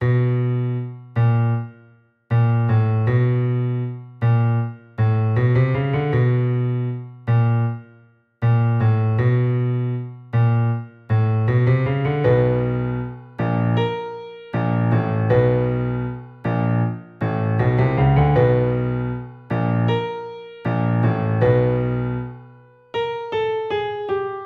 Tag: 157 bpm Trap Loops Piano Loops 4.11 MB wav Key : B FL Studio